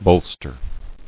[bol·ster]